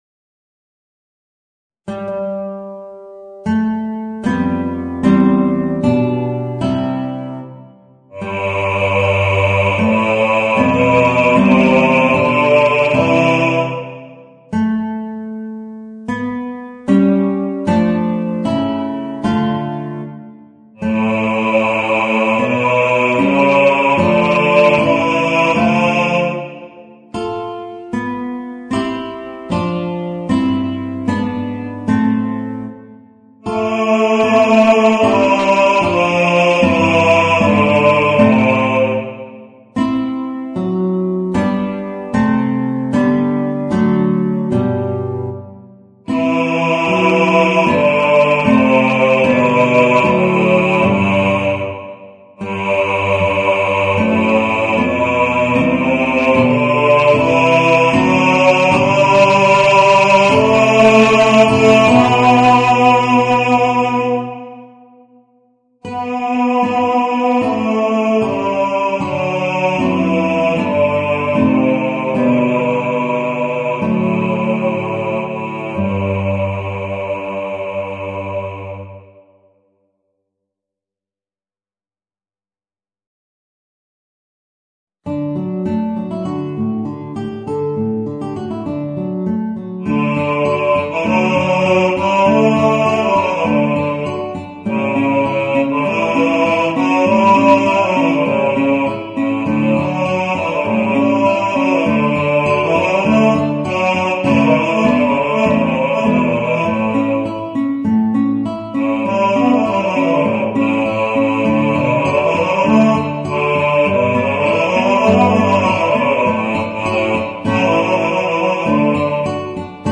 Voicing: Guitar and Bass